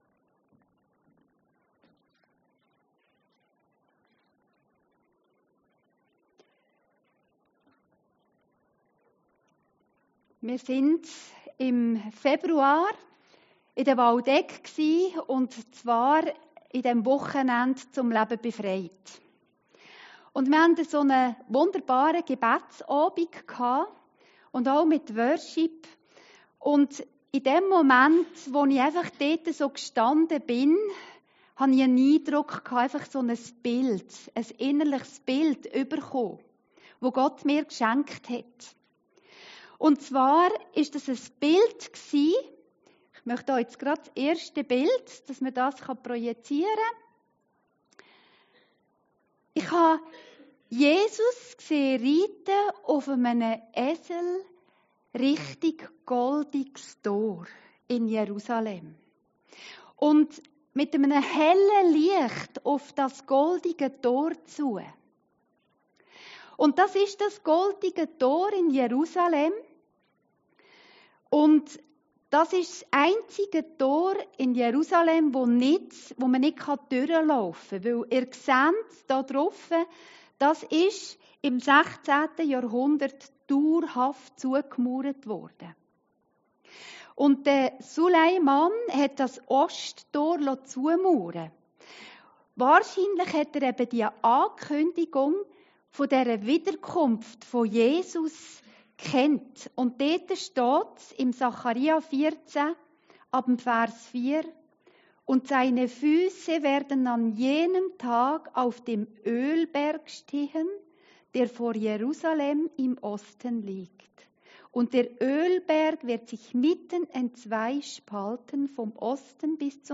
Predigten Heilsarmee Aargau Süd – Jesus kommt wieder